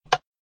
pressurePlateClick.ogg